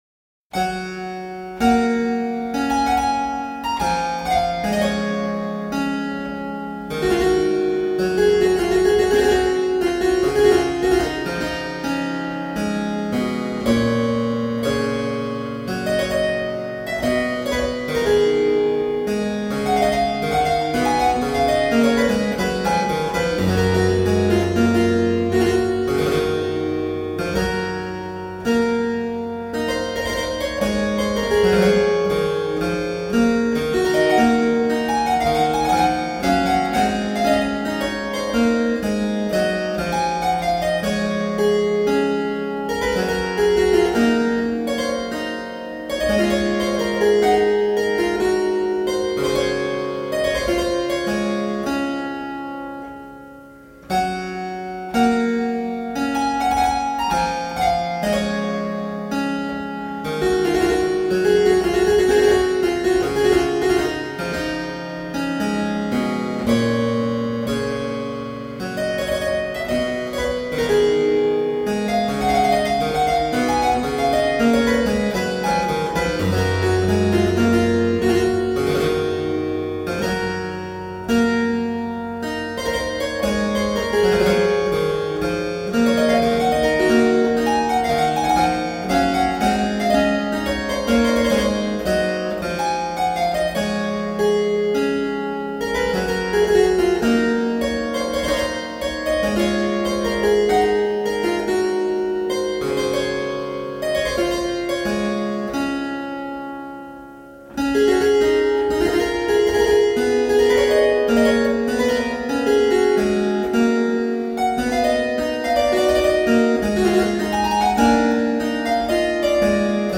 Solo harpsichord music.
Classical, Baroque, Instrumental, Harpsichord